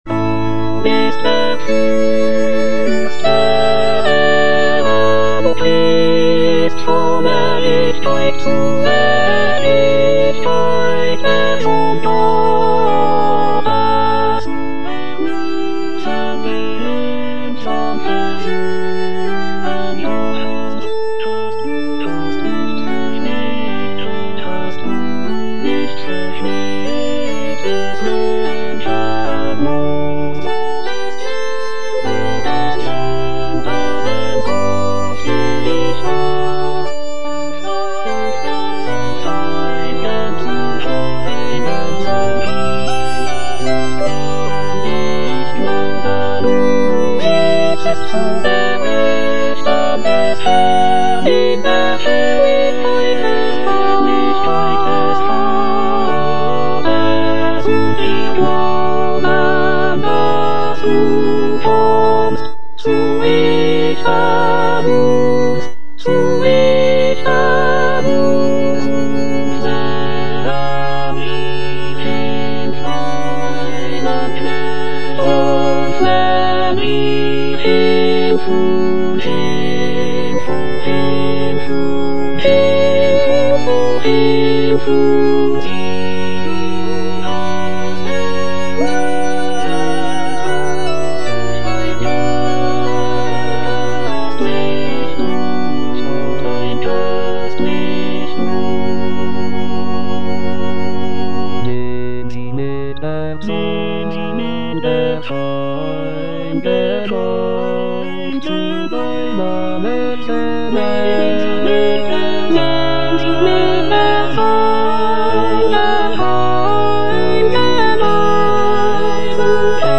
F. MENDELSSOHN - TE DEUM WOO29 Andante come I - Allegro moderato in Canone - Soprano (Emphasised voice and other voices) Ads stop: auto-stop
The work showcases Mendelssohn's mastery of choral writing, featuring powerful vocal harmonies and a grand, celebratory atmosphere. The composition is characterized by its dynamic contrasts, melodic richness, and intricate counterpoint.